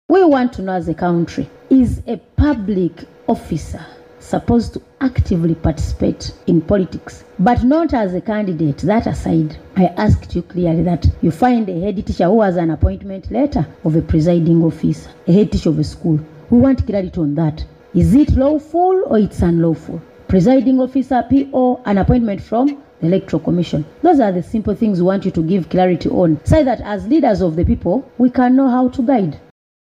While chairing the Committee meeting on Monday, 02 March 2026, Hon. Gorreth Namugga, noted accounts of teachers and head teachers who served as polling agents, returning officers and presiding officers during the elections.
AUDIO: Hon. Namugga